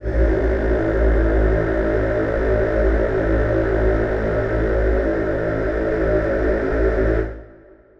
Choir Piano
A1.wav